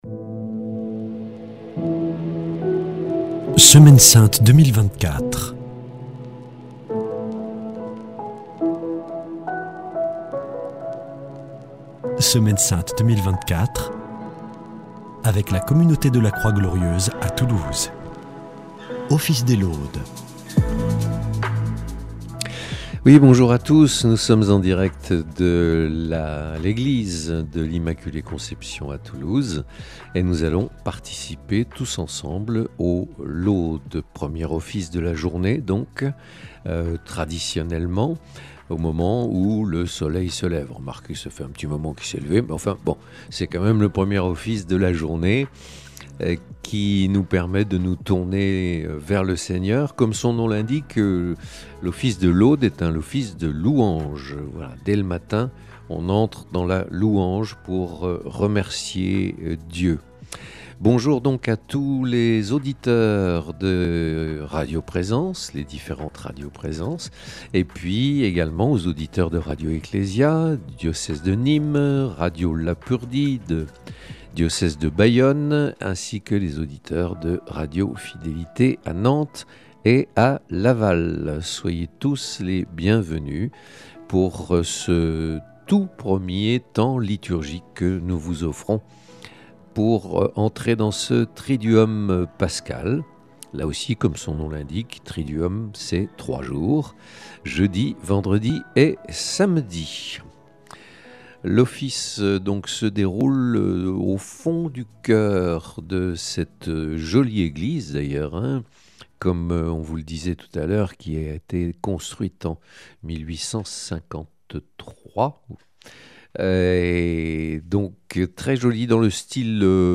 écoutez les laudes chantées par la communauté de la Croix Glorieuse